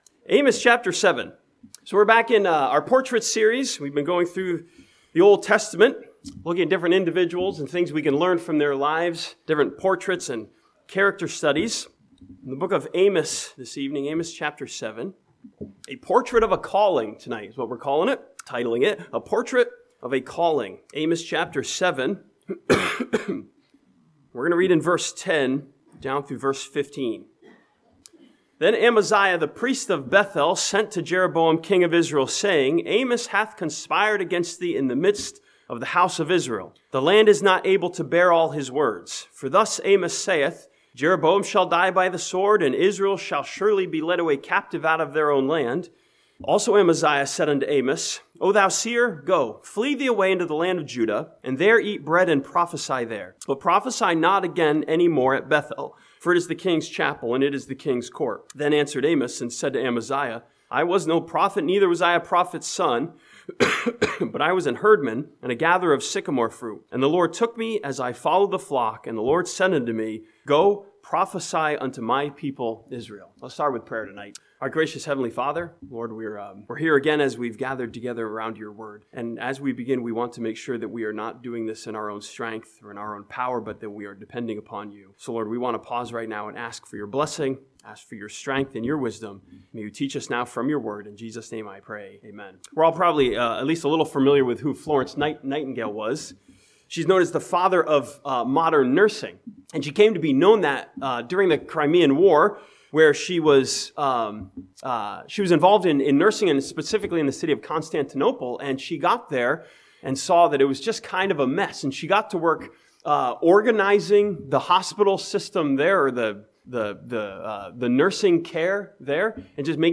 This sermon from Amos 7 takes a look at the life of the Prophet Amos as a portrait of a calling from God.